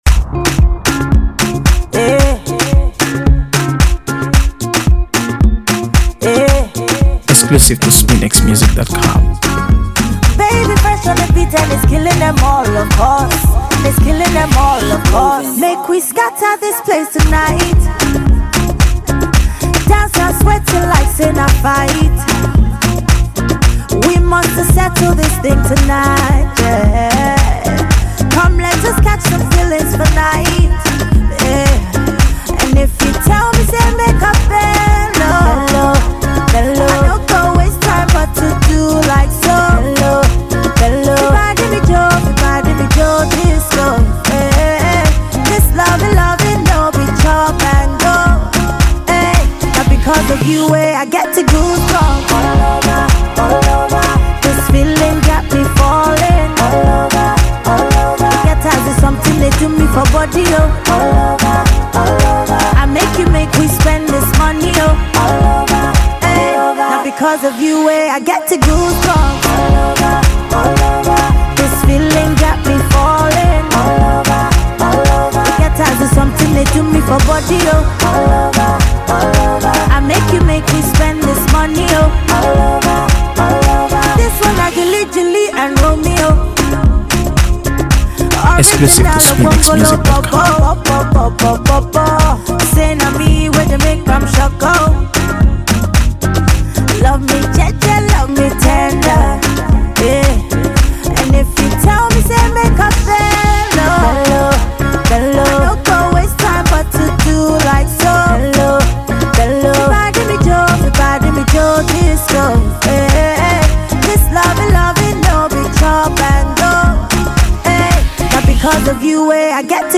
AfroBeats | AfroBeats songs
a melodious tune
blending heartfelt lyrics with infectious rhythms